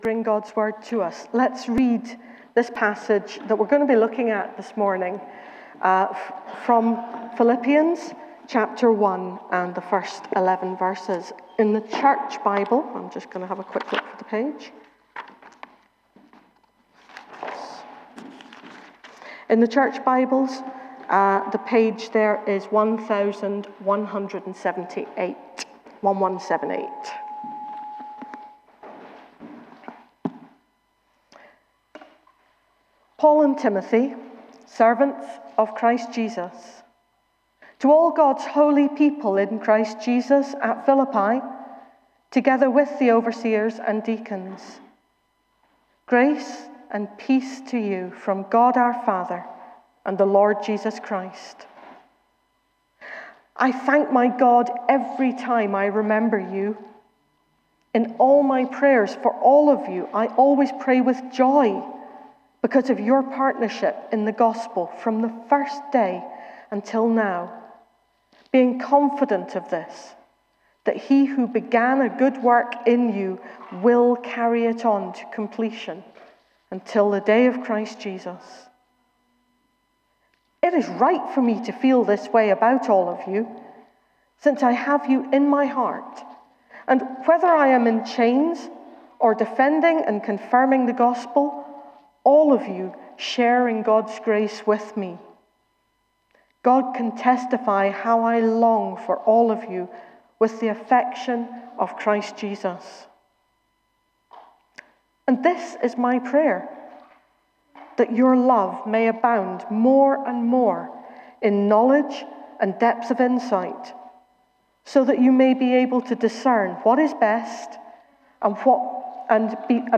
Bridge of Don Baptist Church Sermons